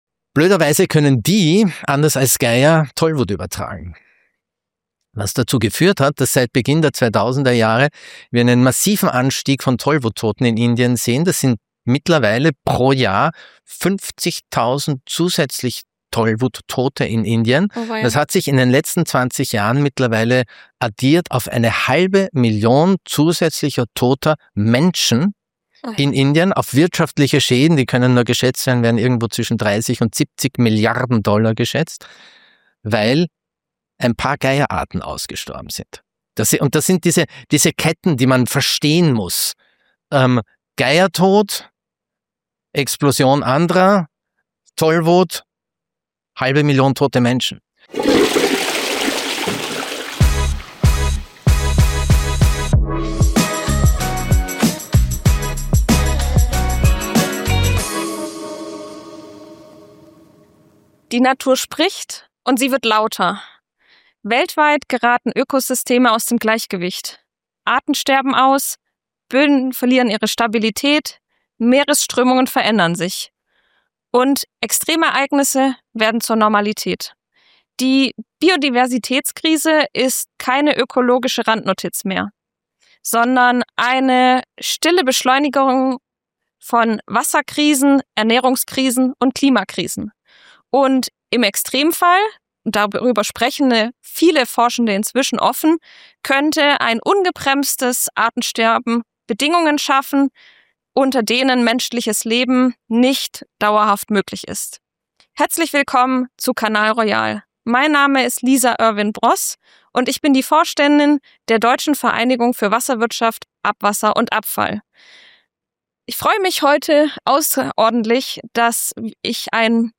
Ein Kanal-Royal-Gespräch über äußerst realistische Szenarien und die Bedeutung von Geschichten im Kampf für die Umwelt.